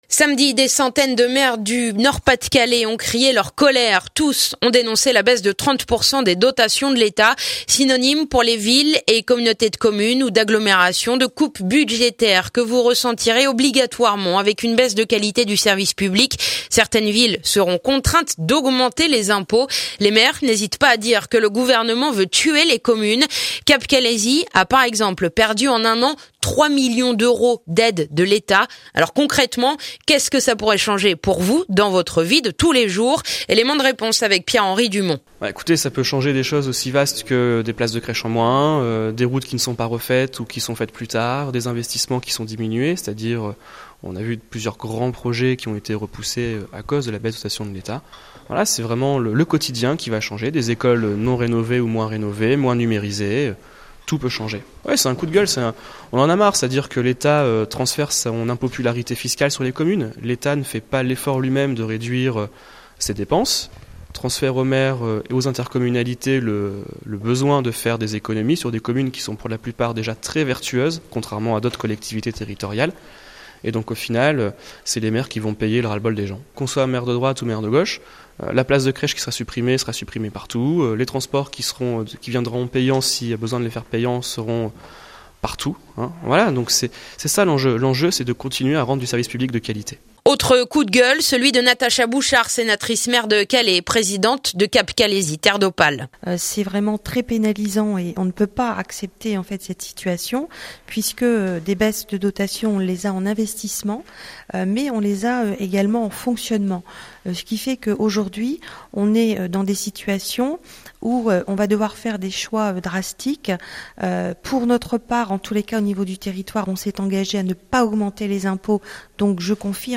Témoignages de maires du calaisis et du montreuillois